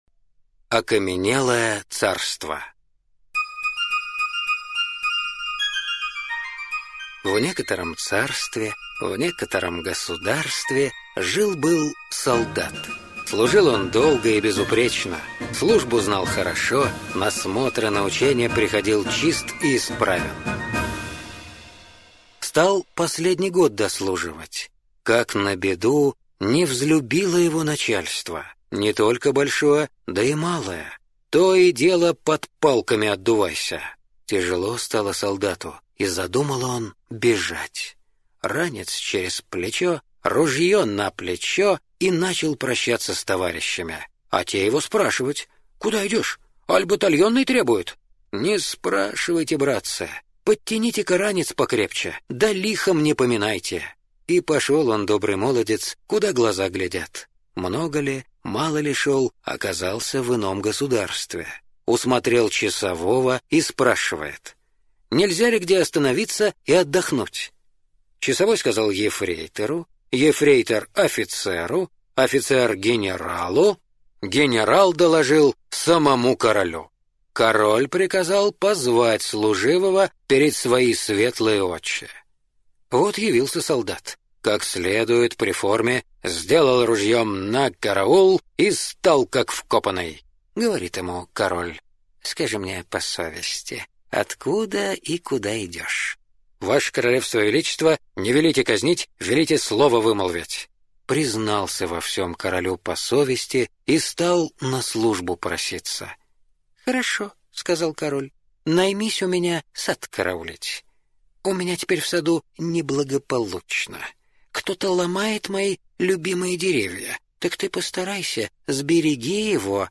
Слушать онлайн аудиосказку "Окаменелое царство":